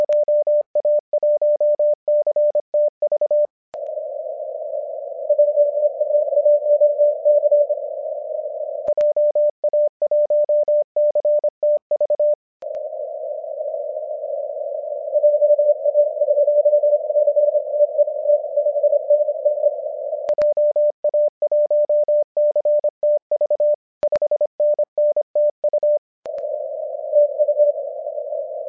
この局は耳も良く、こちらではぎりぎりの信号レベルでも確実に取ってもらえました。
これは一番良く聞こえていた21MHz CWでのQSOです。
px0ff_21mhz.mp3